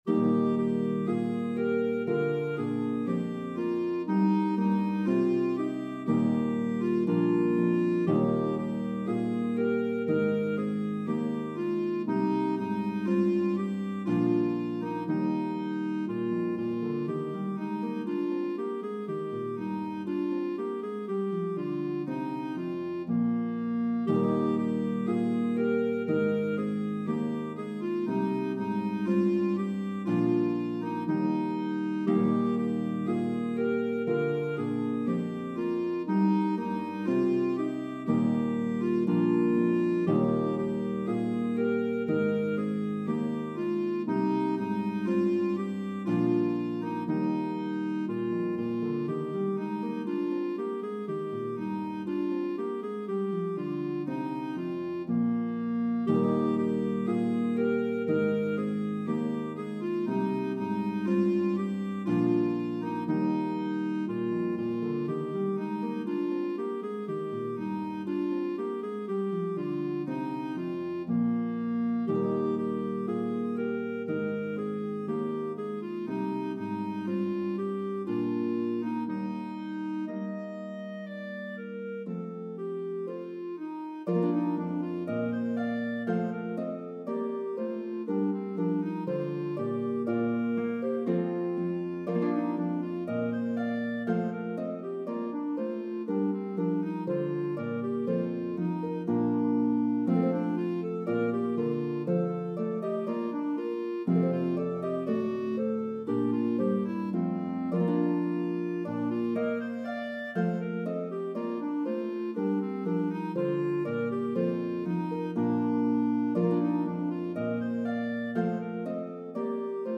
Harp and Clarinet version